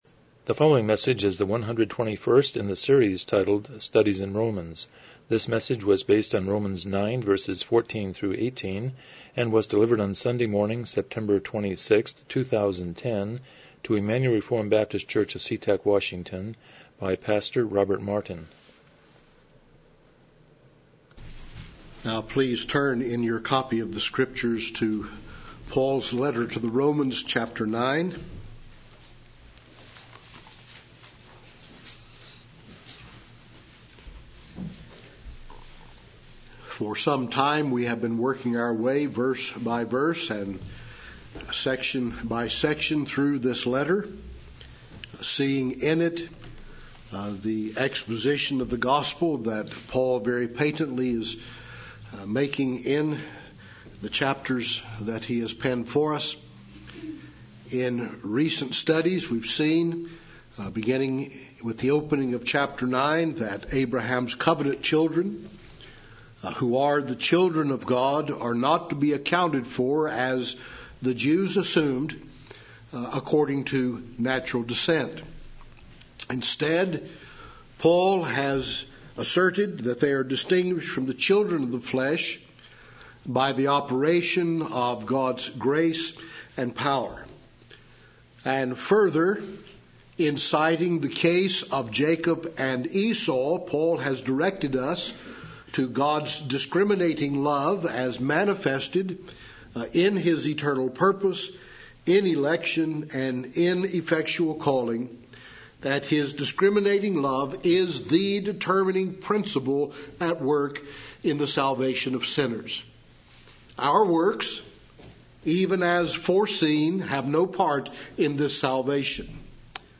Romans 9:14-18 Service Type: Morning Worship « 106 Chapter 21.1-2